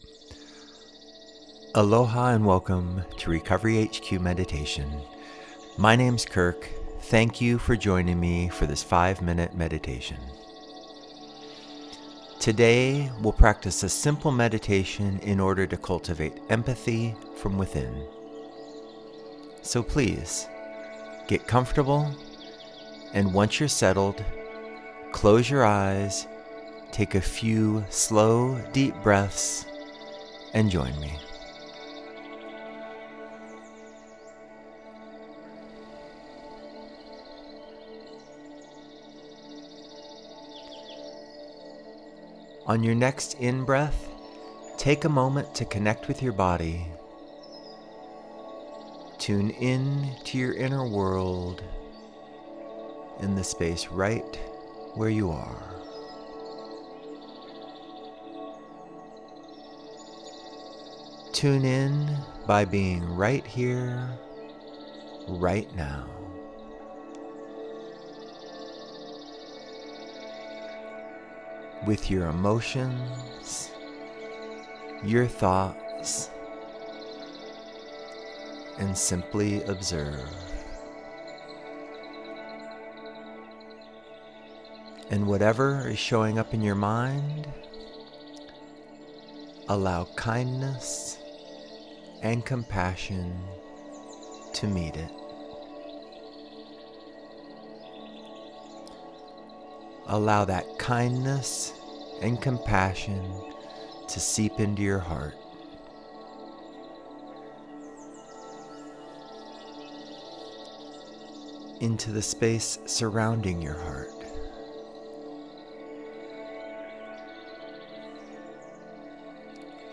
Empathy-Meditation.mp3